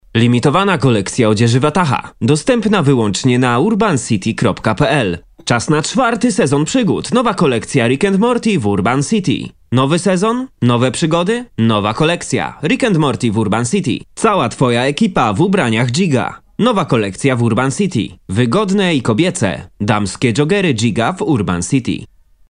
男波02 波兰语男声 略年轻 干音 沉稳|科技感|积极向上|素人